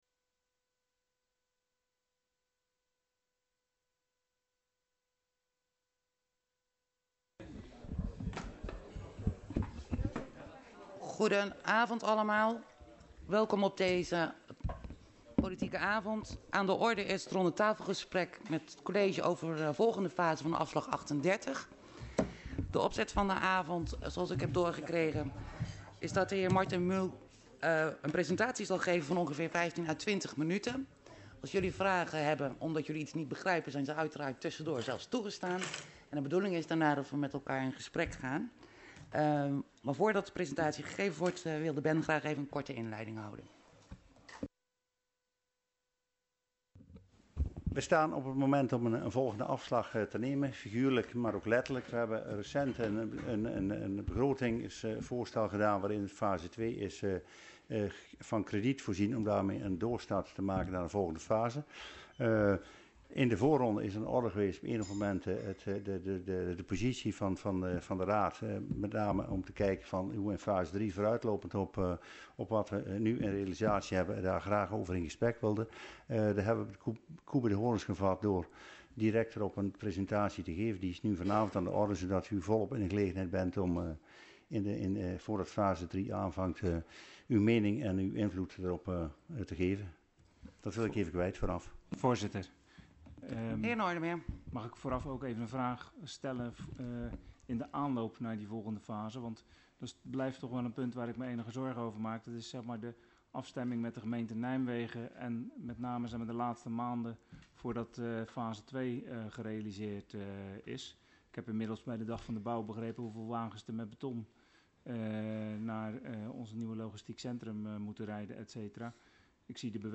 Locatie Hal, gemeentehuis Elst Voorzitter mevr. A.J. Versluis Toelichting Ronde tafel gesprek met college over volgende fase project Afslag 38 Agenda documenten 16-06-21 Opname inzake Ronde tafel gesprek met college over volgende fase project Afslag 38.MP3 21 MB